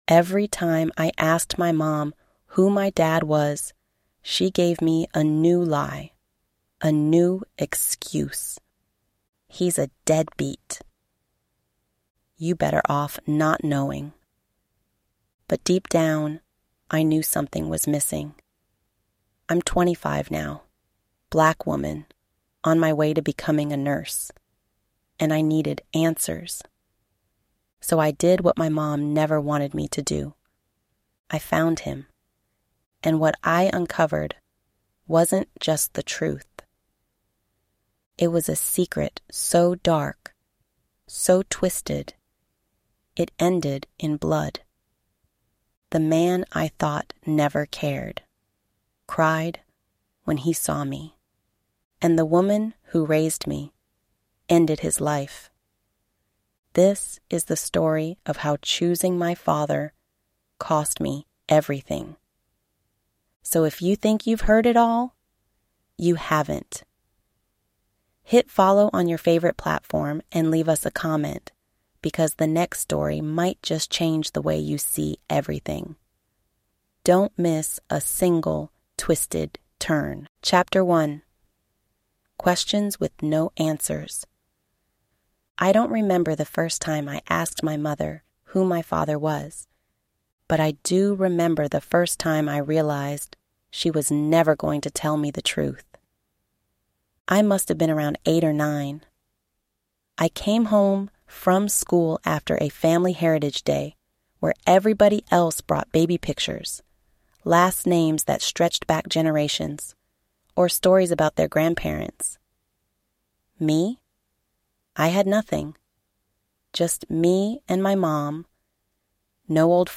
Told from the daughter’s point of view, this suspenseful audiobook explores the dangerous power of parental manipulation, lost love, and the cost of choosing truth over lies.